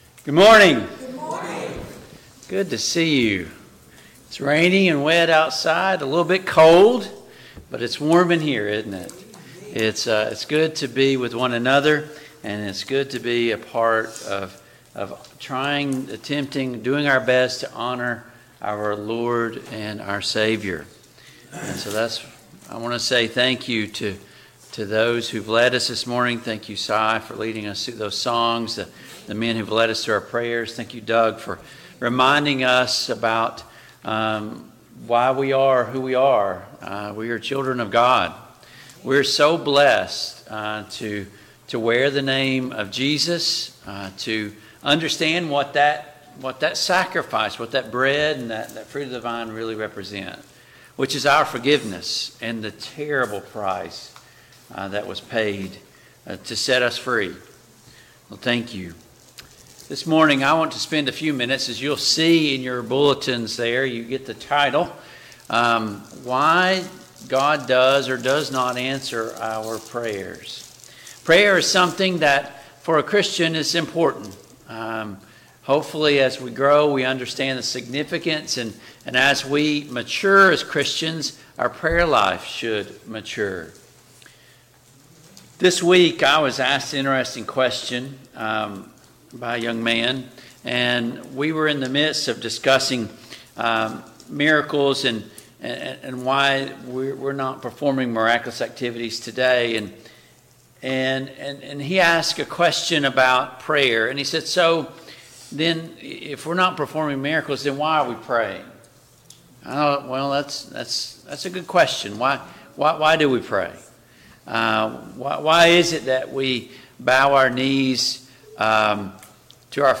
Luke 18:1-8 Service Type: AM Worship Download Files Notes Topics